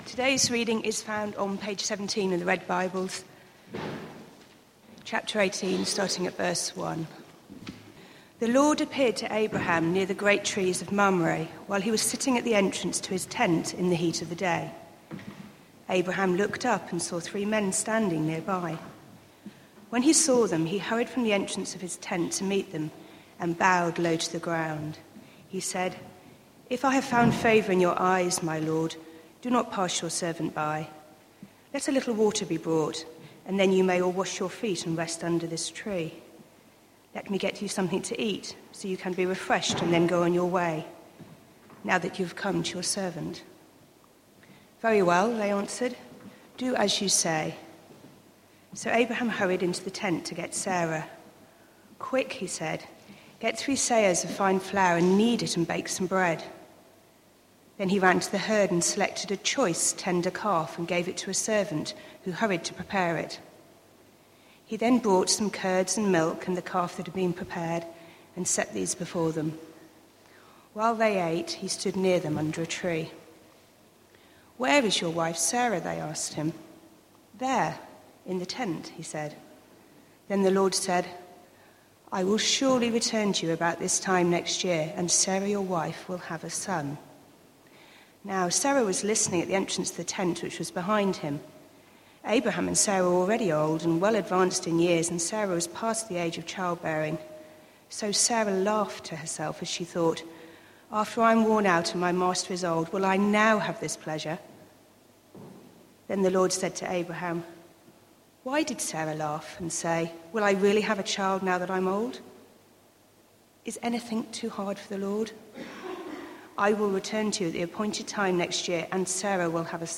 Christ Church Morning Service
Passage: Genesis 18:1-15 Series: From small beginnings Theme: Sermon